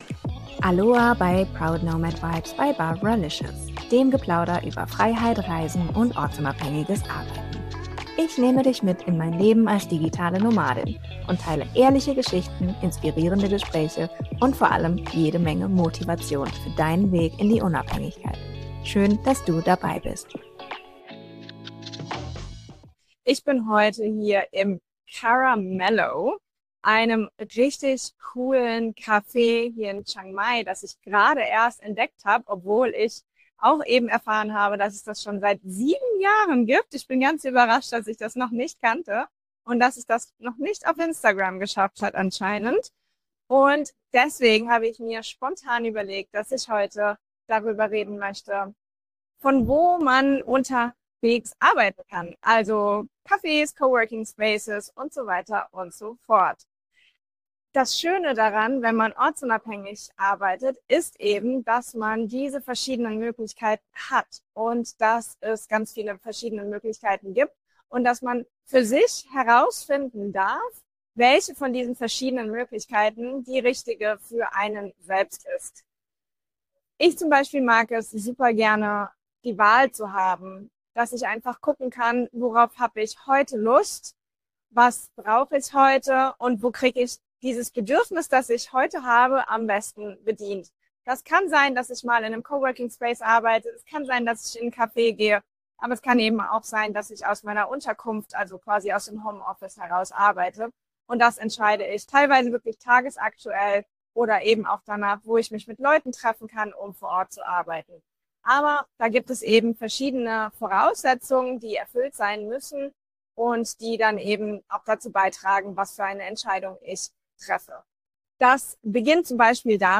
Ich nehme dich mit ins Café Caramellow in Chiang Mai und teile meine Erfahrungen aus über 10 Jahren ortsunabhängigem Arbeiten.